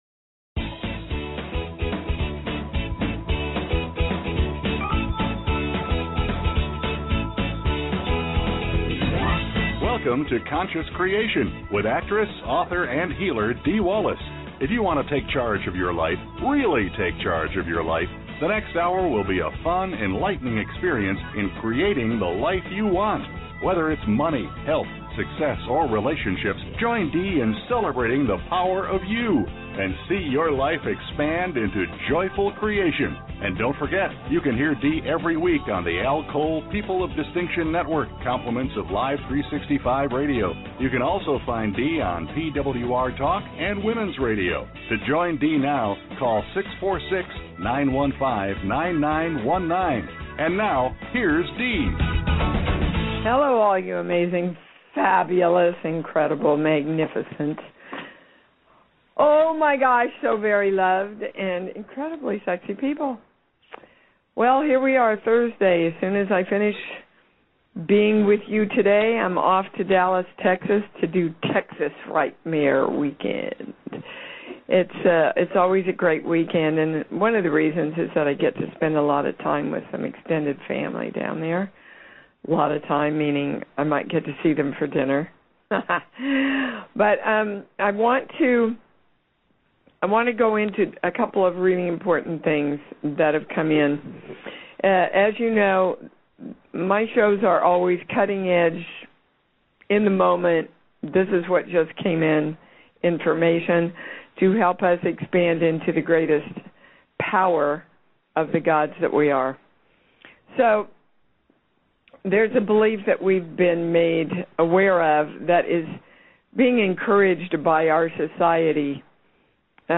Talk Show Episode, Audio Podcast, Conscious Creation and with Dee Wallace on , show guests , about Core Truths,Balanced Life,Energy Shifts,Spirituality,Spiritual Archaeologist,Core Issues,Spiritual Memoir,Healing Words,Consciousness,Self Healing, categorized as Health & Lifestyle,Alternative Health,Energy Healing,Kids & Family,Philosophy,Psychology,Self Help,Spiritual,Psychic & Intuitive